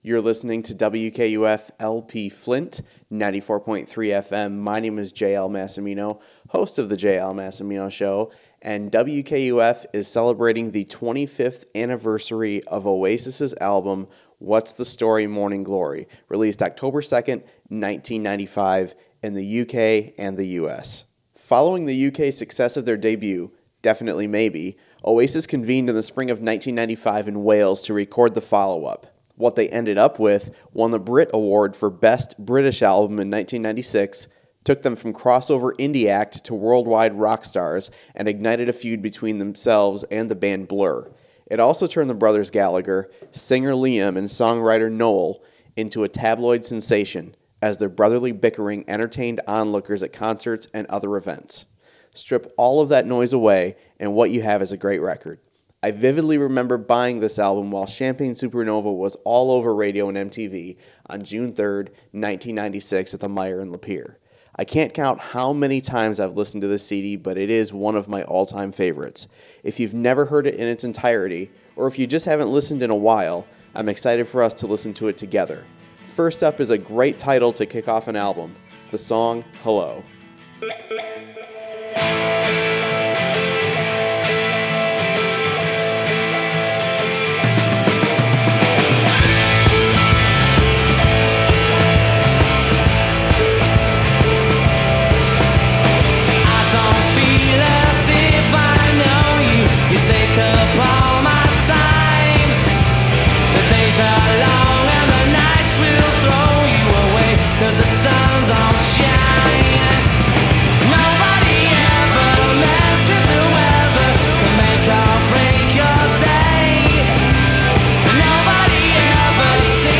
Genre: Britpop.